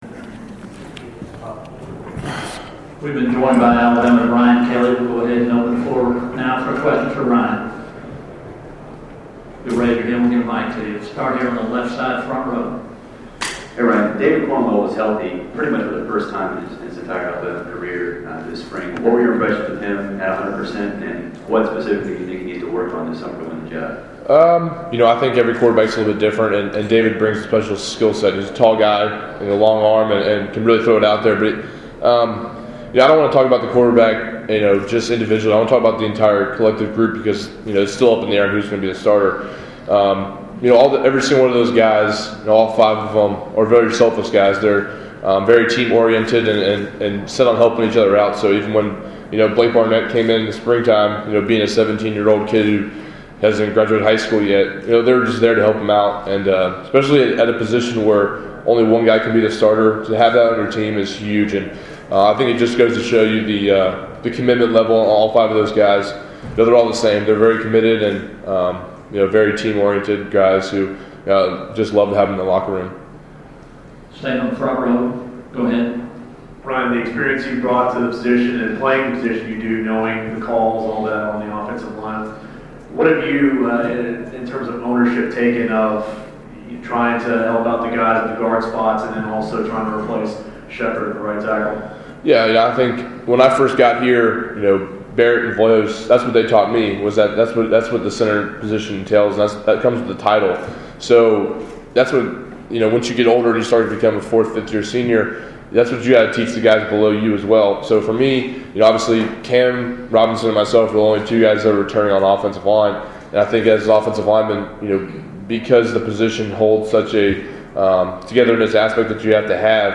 Alabama center at SEC Media Days 2015